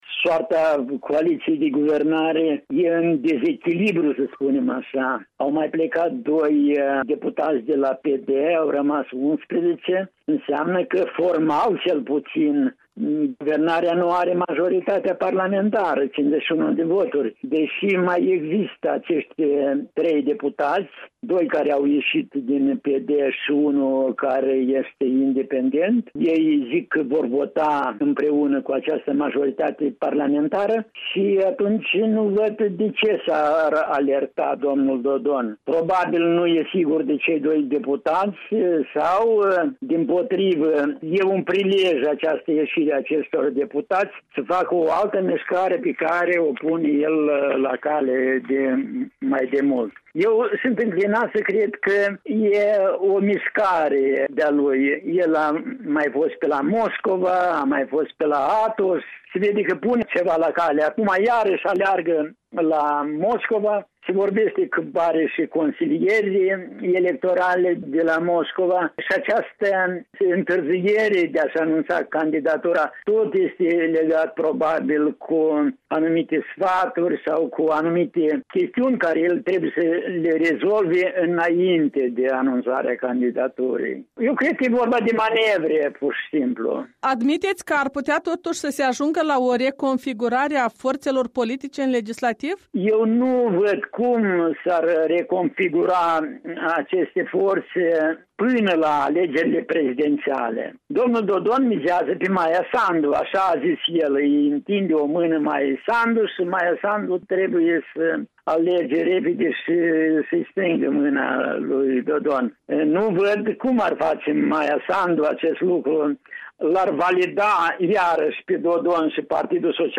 Un interviu cu analistul politic